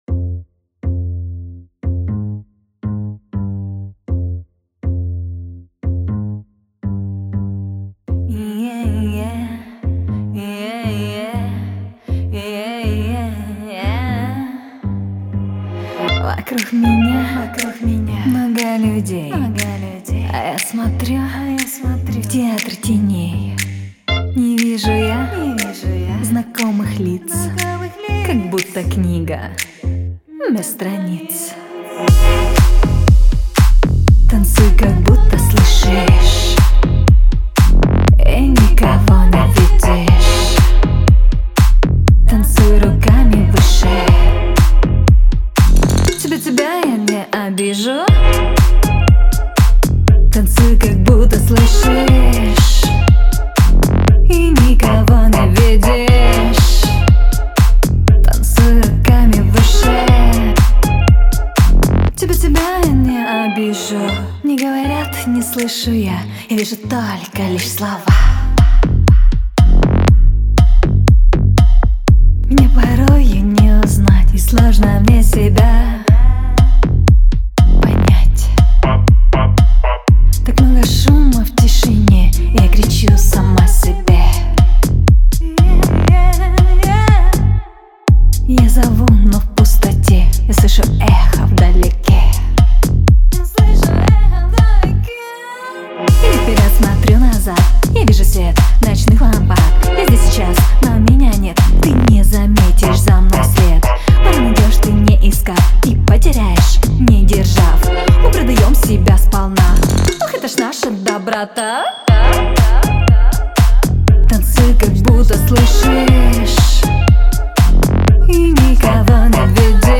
Русский поп